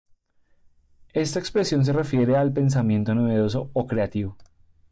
Pronounced as (IPA)
/pensaˈmjento/